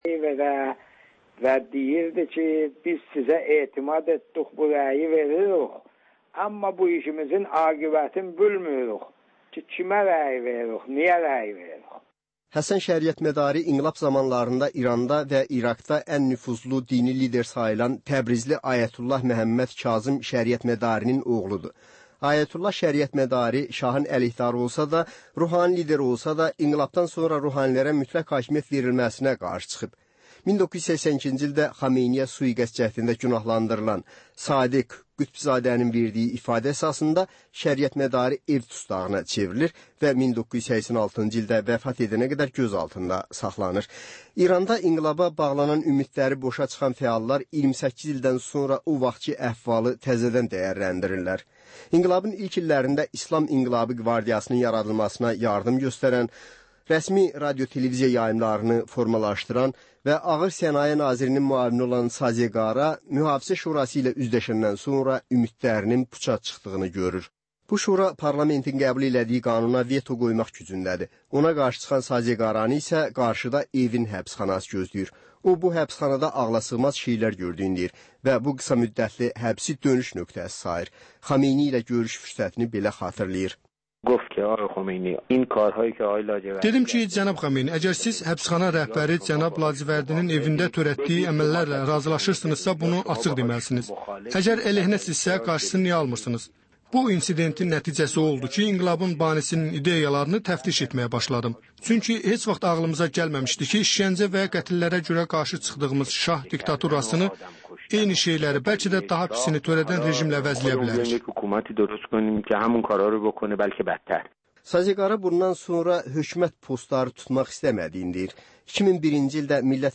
Həftənin aktual məsələsi haqda dəyirmi masa müzakirəsi